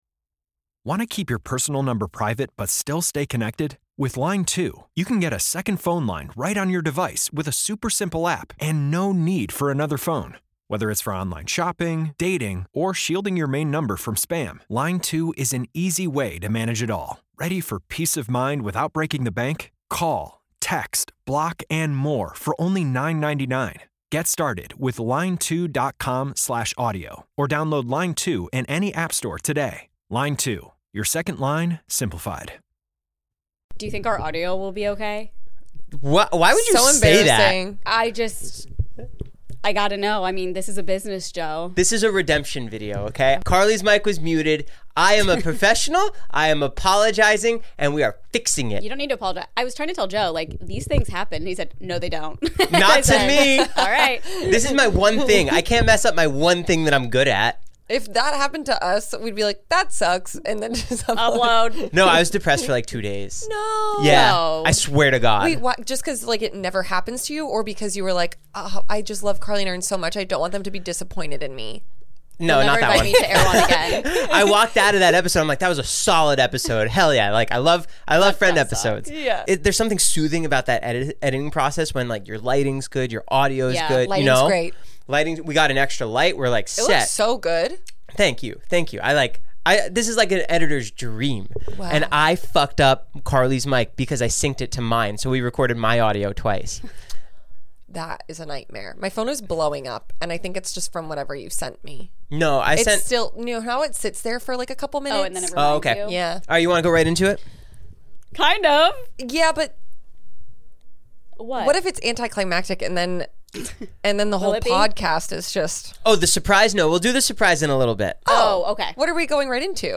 Society & Culture, Tv Reviews, Comedy Interviews, Film Interviews, Comedy, Tv & Film, Education, Hobbies, Music Commentary, Music Interviews, Relationships, Leisure, Health & Fitness, Self-improvement, Music, Fitness